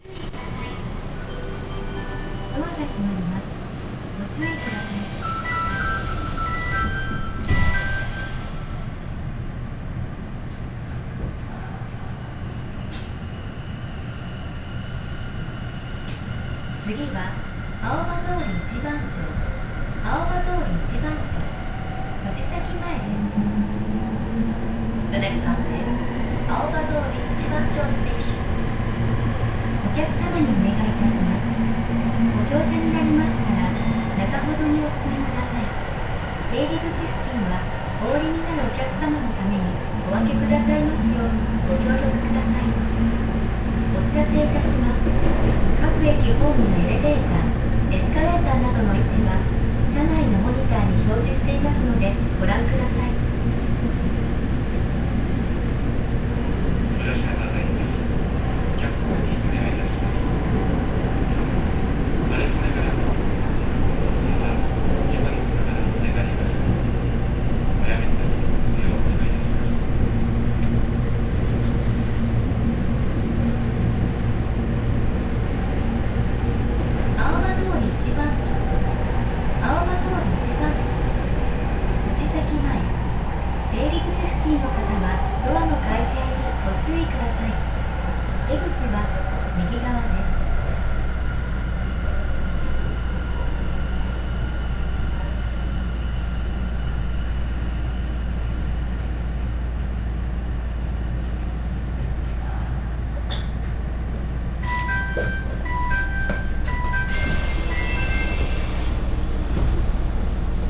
〜車両の音〜
・1000N系走行音
【東西線】大町西公園→青葉通一番町（1分50秒：602KB）
VVVFは三菱IGBTですが、リニア地下鉄であるためやや聞き慣れない音となります。
尚、閉扉前には必ず案内放送が流れます。自動放送の声は南北線同様、東京メトロなどでお馴染みの声となります。